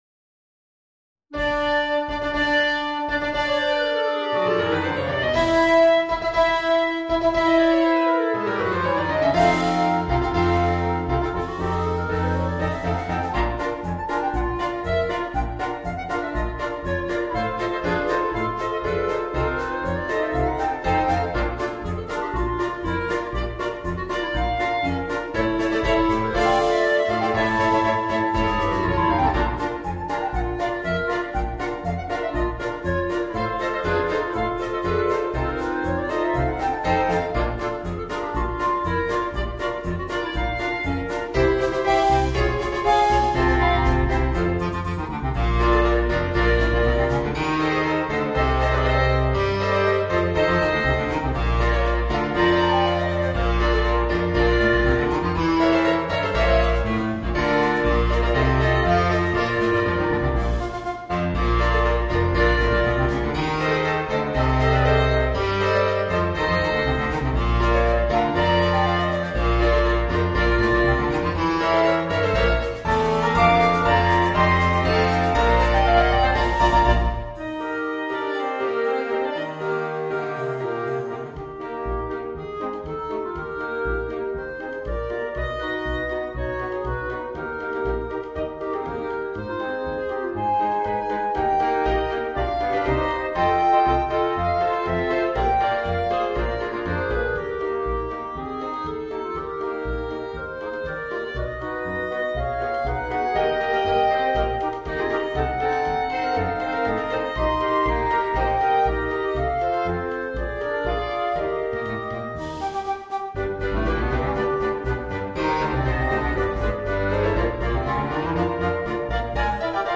Voicing: 5 Clarinets and Rhythm Section